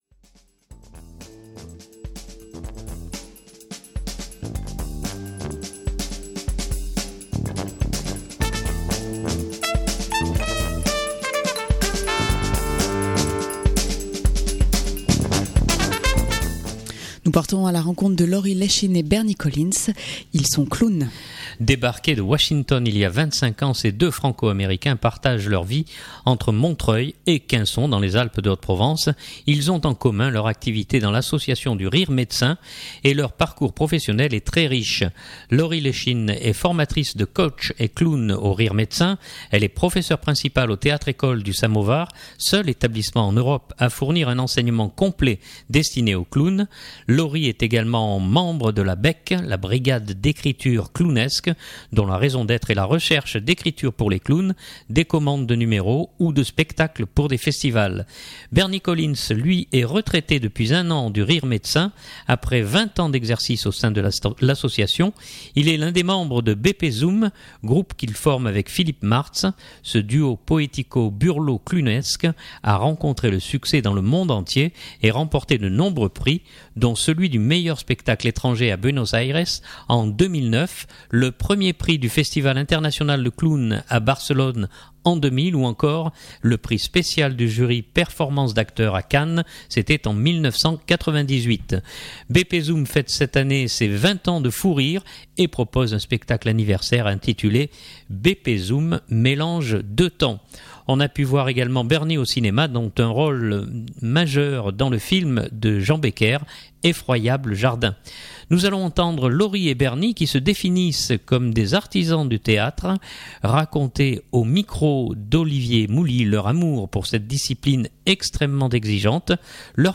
Reportage en deux temps, en clin d’œil au BP Zoom.